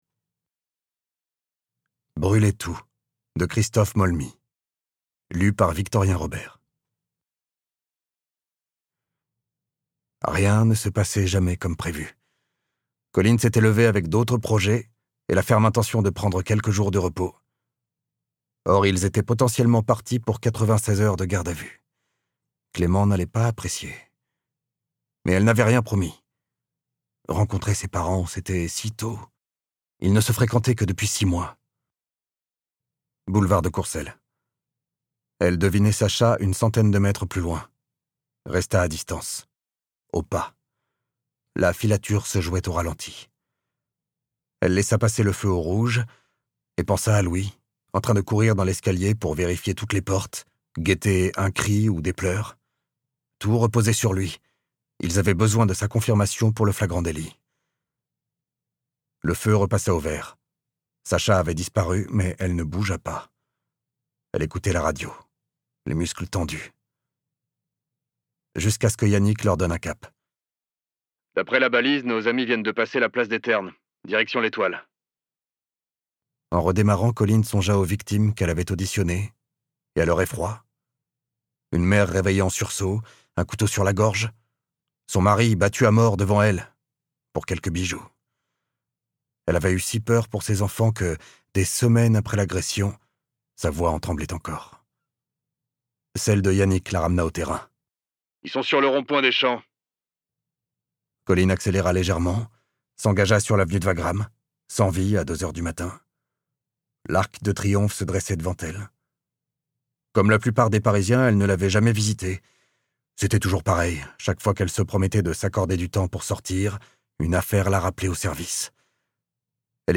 Prix Quai des Orfèvres 2026 Interprétation humaine Durée : 06H51 22 , 95 € Ce livre est accessible aux handicaps Voir les informations d'accessibilité